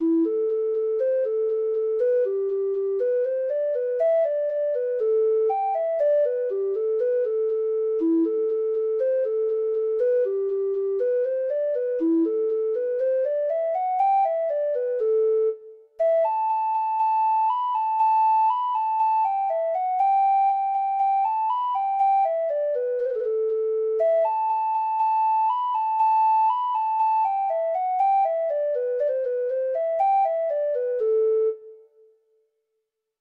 Traditional Sheet Music
Irish